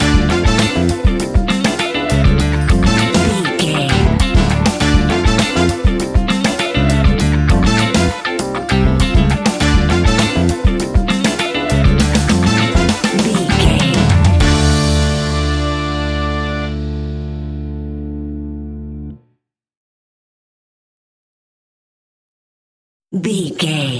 Aeolian/Minor
D
cuban music
World Music
uptempo
drums
bass guitar
percussion
brass
saxophone
trumpet
fender rhodes
clavinet